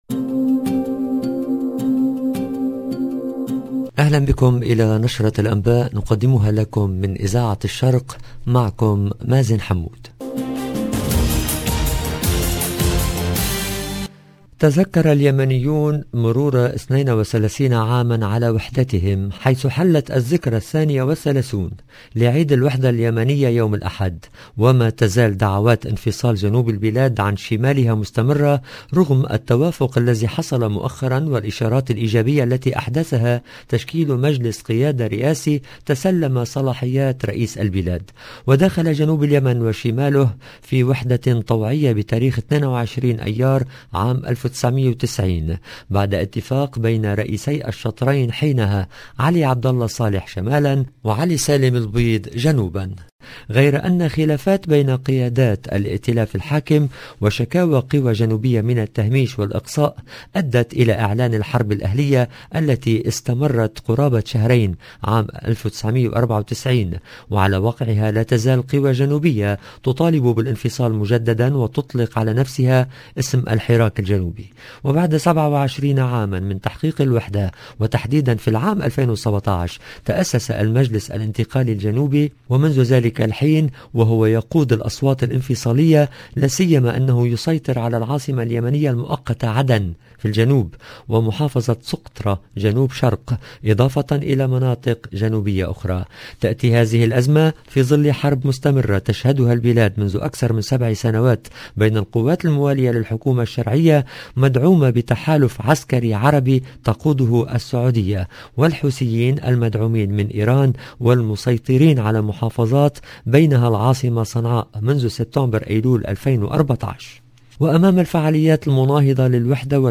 EDITION DU JOURNAL DU SOIR EN LANGUE ARABE DU 22/5/2022